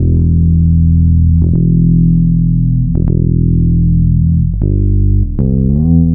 Track 11 - Bass.wav